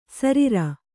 ♪ sarira